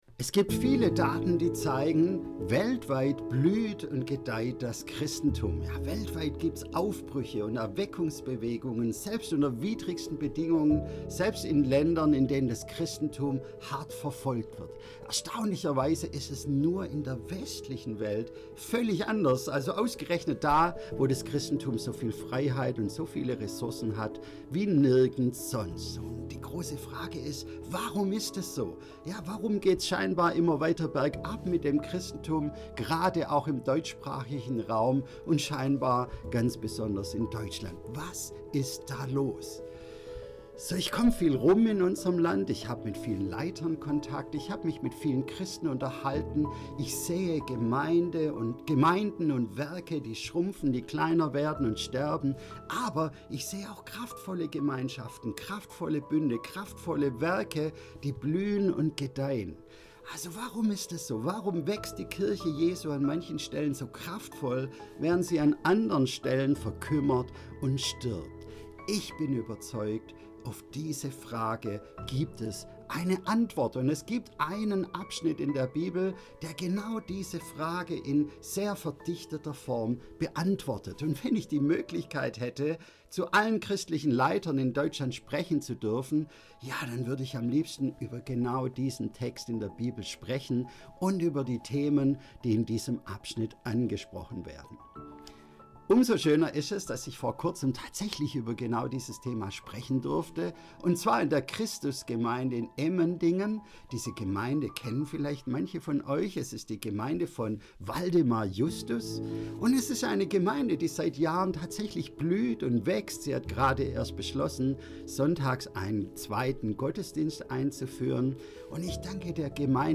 Dieses Video enthält eine Predigt, die am 23.11.2025 in der Christusgemeinde Emmendingen gehalten wurde.